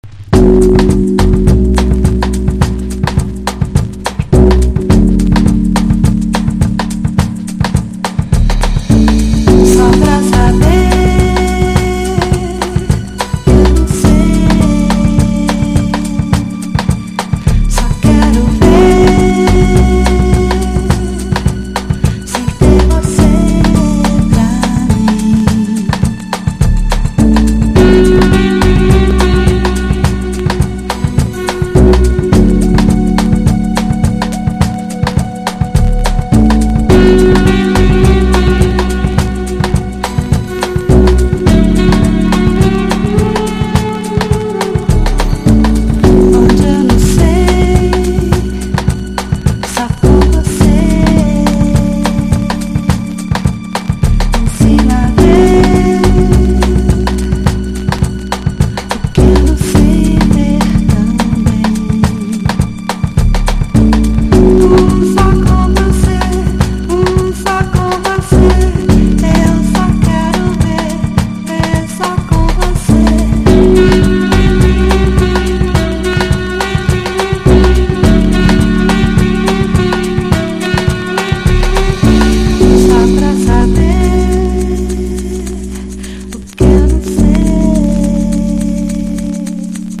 CROSSOVER / LOUNGE# BREAK BEATS / BIG BEAT
ボッサのリズムを取り入れたクラブジャズ良作！